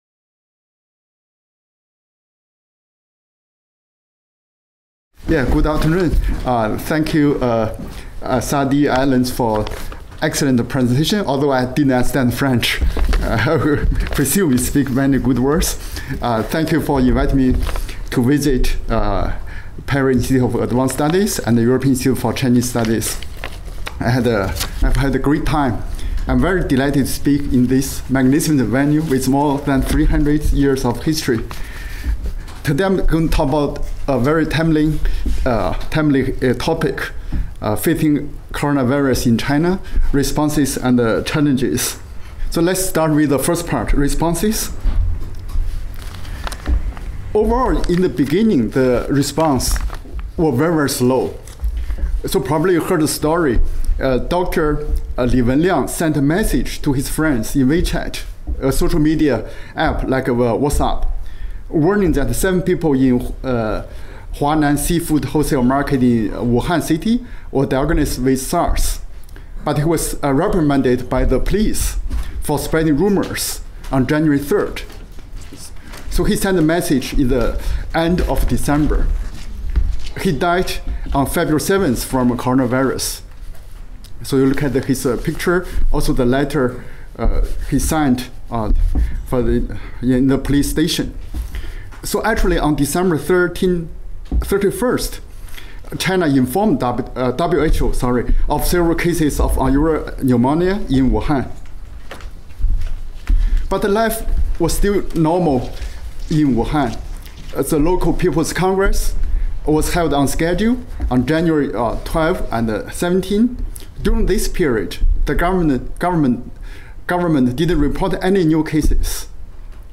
La conférence